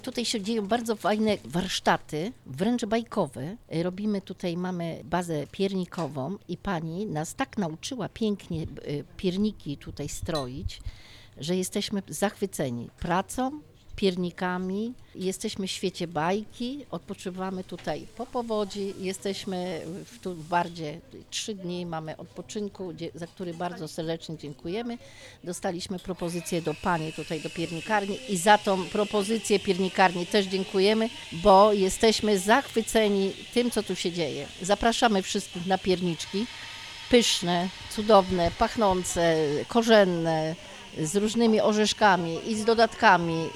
Z powodzianami spotkaliśmy się na zakończenie warsztatów zdobienia pierników.